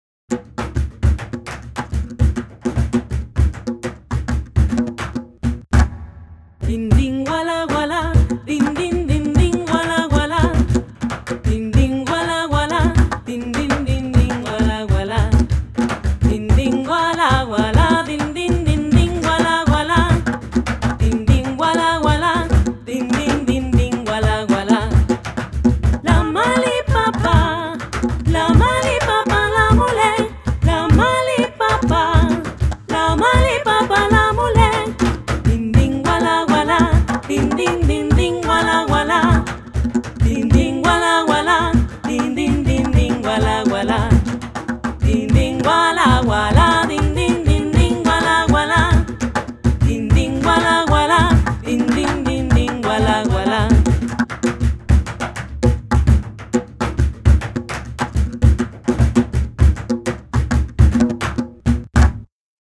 Tradicional garífuna- Guatemala